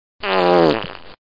Download Wet Fart sound effect for free.
Wet Fart